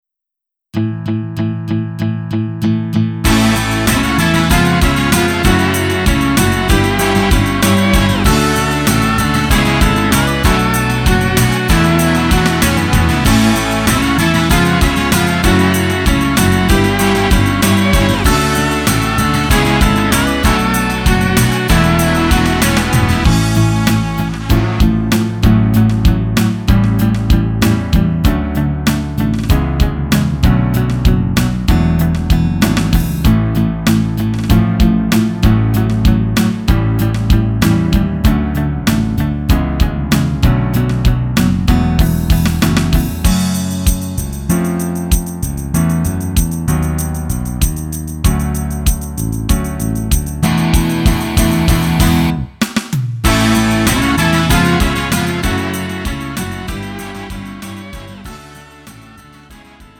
음정 -1키 3:07
장르 가요 구분